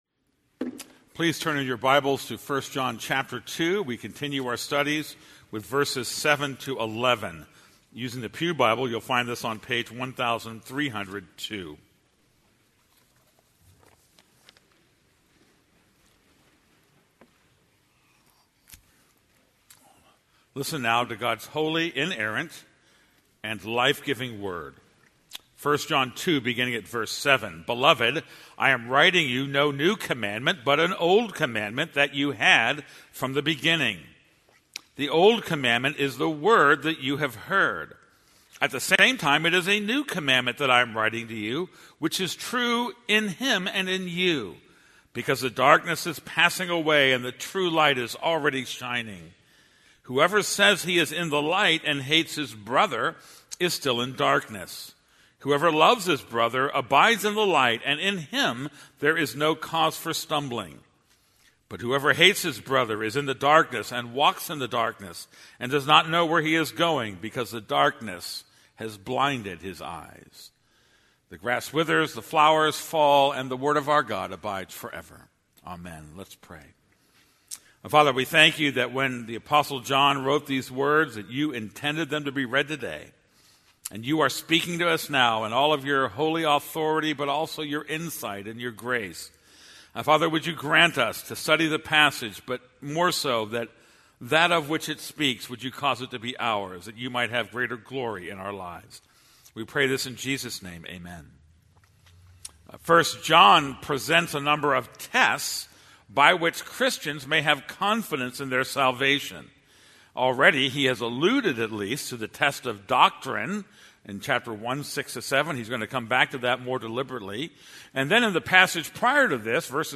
This is a sermon on 1 John 2:7-11.